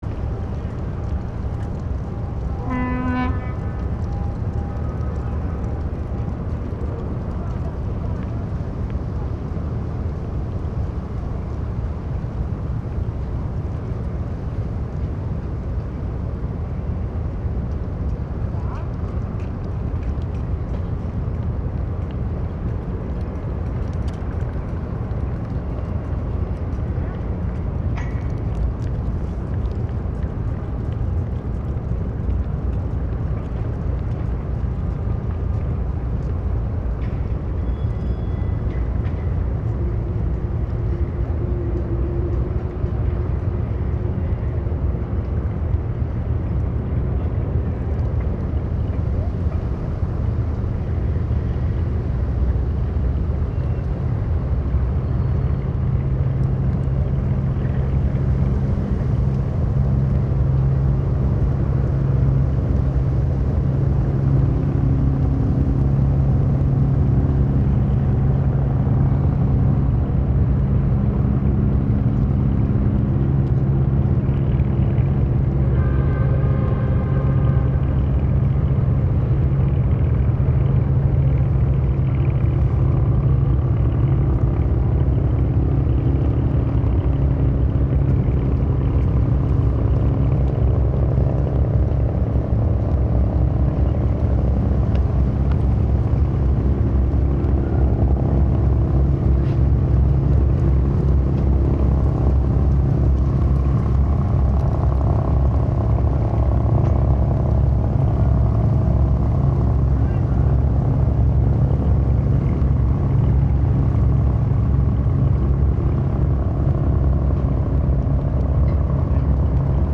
Sound recorded in Beijing, Shanghai, Hong Kong and Macao.
- Sound from the Pudong side of the Huangpu river, Shanghai (3) (3:51)
at_the_river_in_pudong_shanghai_3.mp3